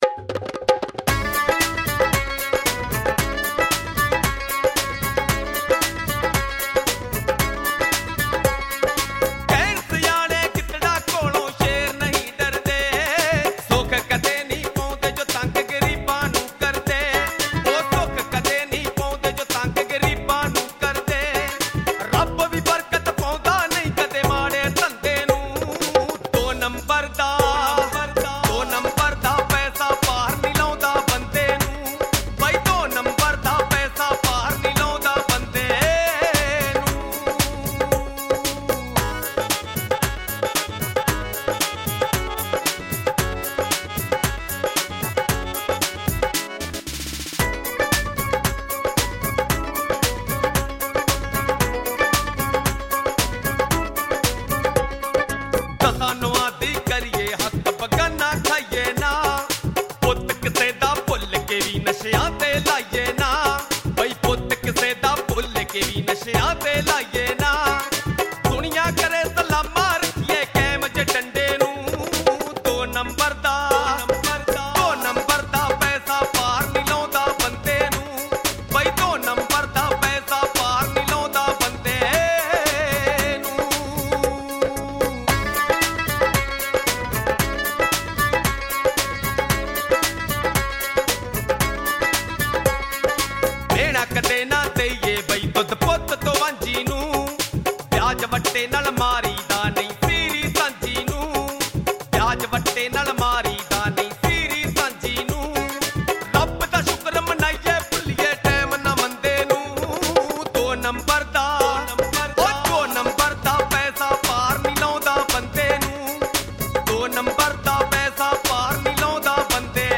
All New Punjabi songs Available